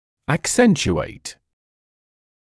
[ək’senʧueɪt][эк’сэнчуэйт]обострять, углублять, усиливать, усугублять; выделять